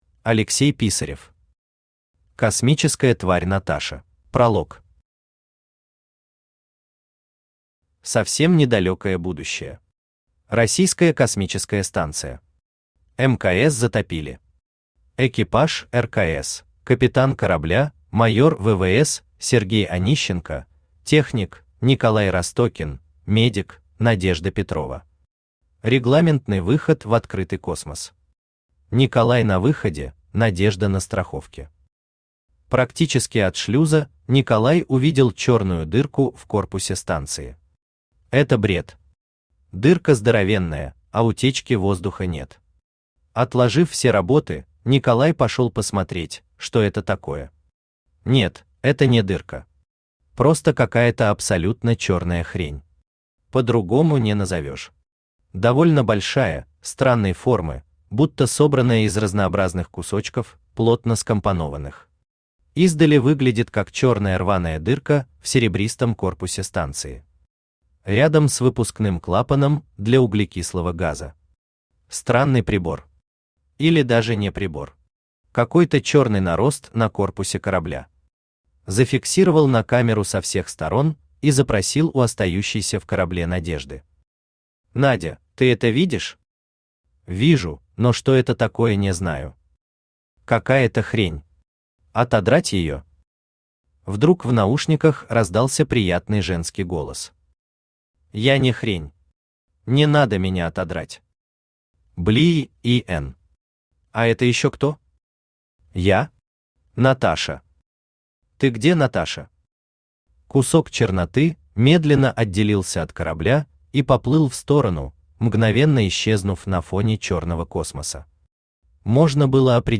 Аудиокнига Космическая тварь Наташа | Библиотека аудиокниг
Aудиокнига Космическая тварь Наташа Автор Алексей Дмитриевич Писарев Читает аудиокнигу Авточтец ЛитРес.